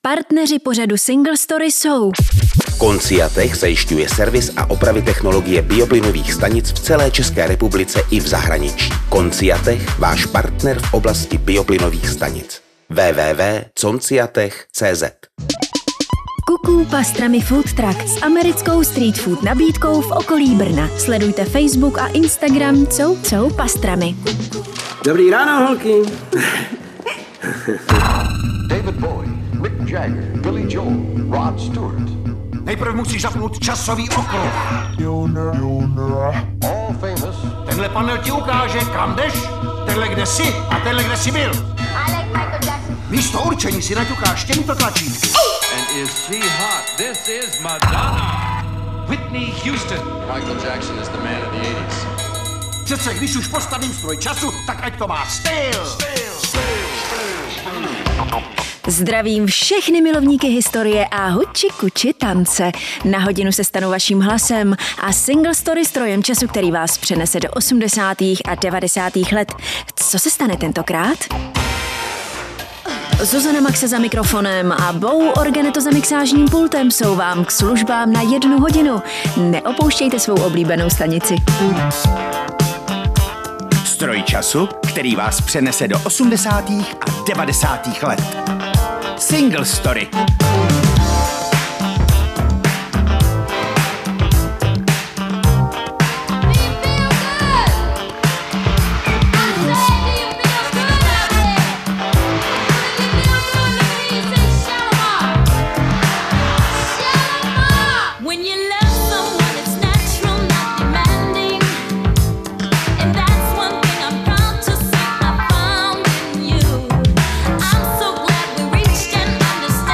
Skvělá hudba, nečekané historické okolnosti a styl se stanou vaším strojem času.